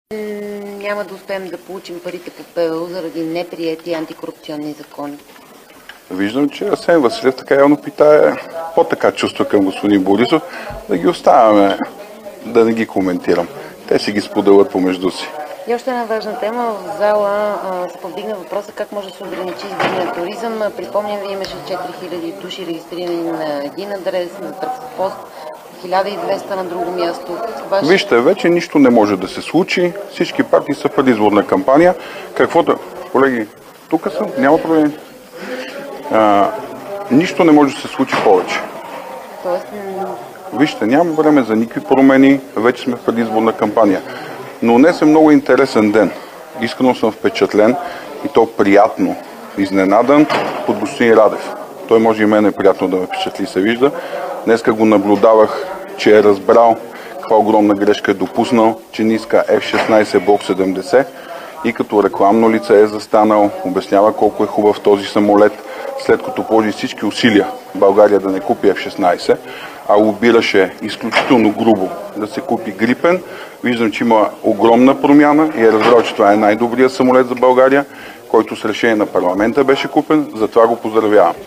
11.05 - Парламентарен контрол.
- директно от мястото на събитието (Народното събрание)